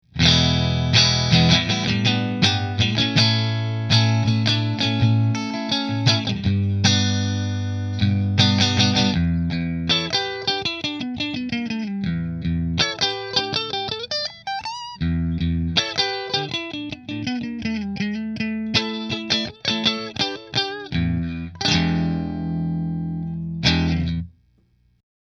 Fender Partscaster Position 2 Through Fender